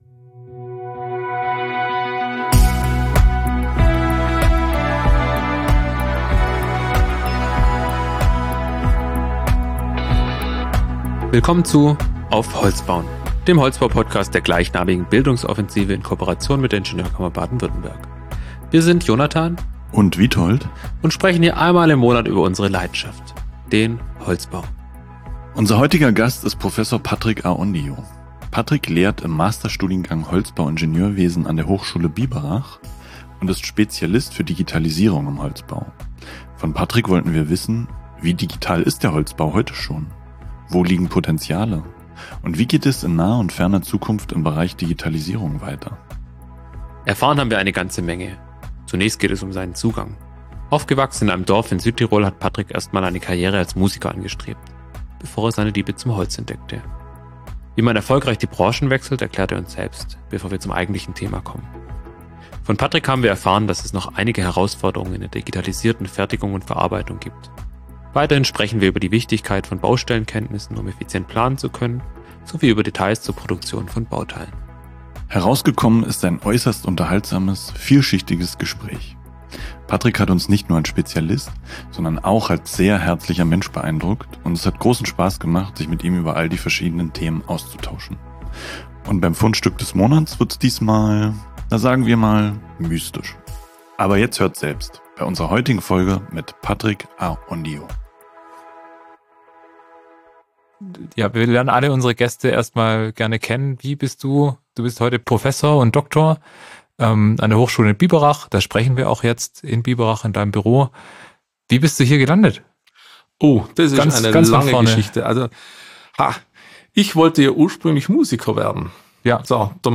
Herausgekommen ist ein äußerst unterhaltsames, vielschichtiges Gespräch.